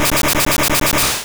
Gust.wav